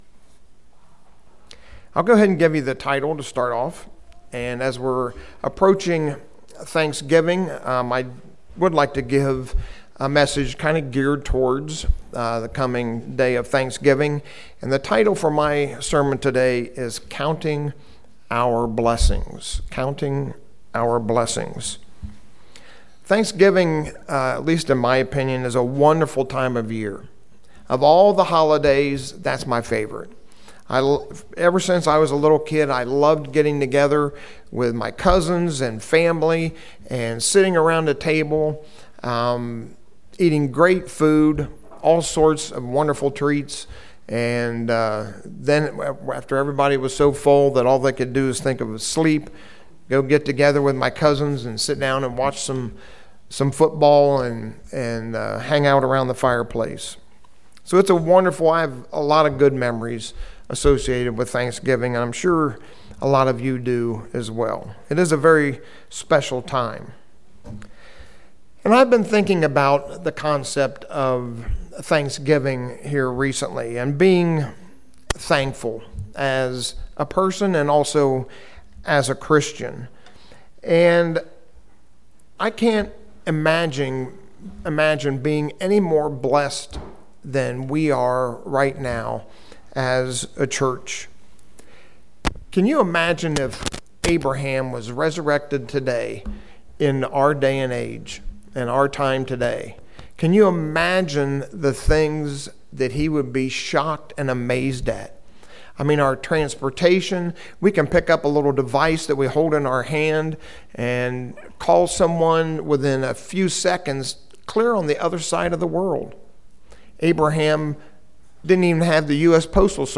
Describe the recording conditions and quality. Given in Lewistown, PA